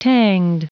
Prononciation du mot tanged en anglais (fichier audio)
Prononciation du mot : tanged